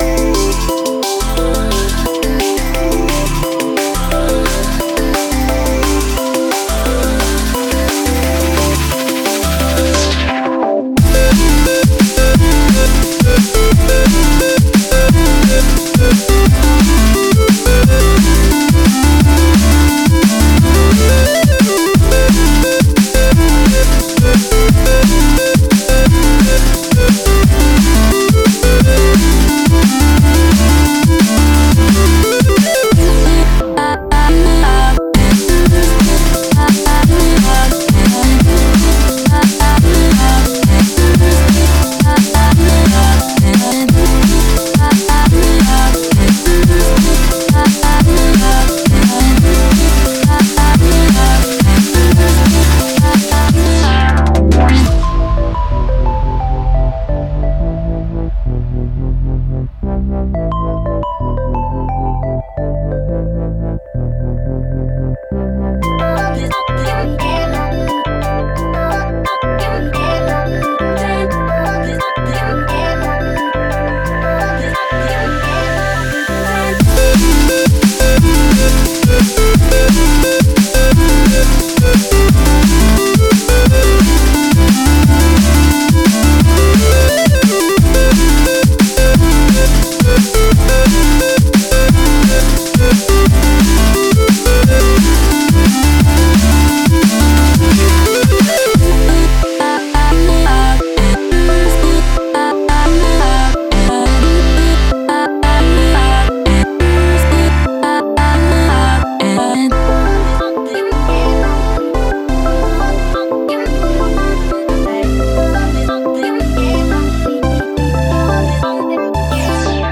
BPM175
Audio QualityPerfect (High Quality)
Comments[DRUM & BASS]